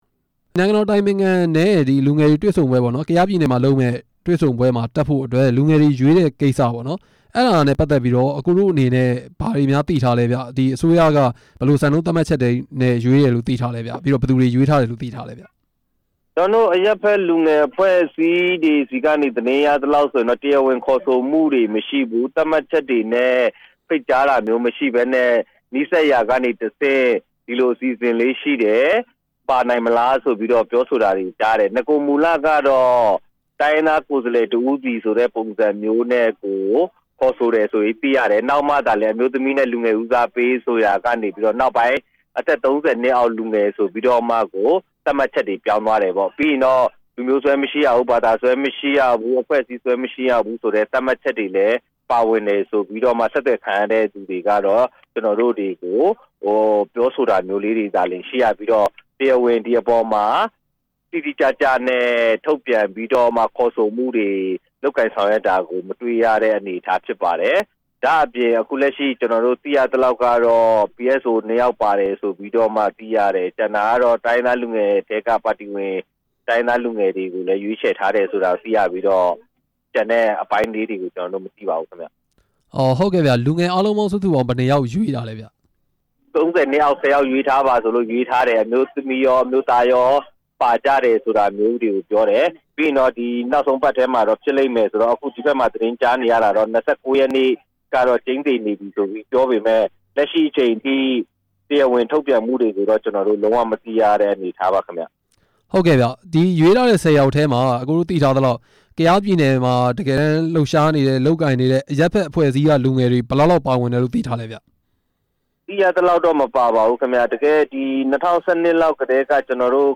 ကယားပြည်နယ်မှာ ကျင်းပမယ့် လူငယ်စကားဝိုင်းအကြောင်း မေးမြန်းချက်